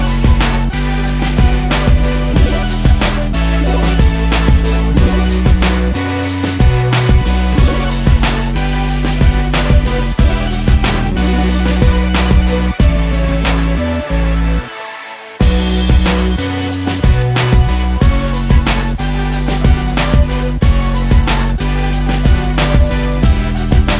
Duet Version R'n'B / Hip Hop 3:39 Buy £1.50